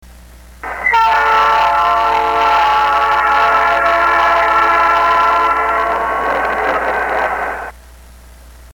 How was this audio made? Playback of the original wave file recorded by a direct connection to the computer's speaker out jack and played back through a larger, higher quality speaker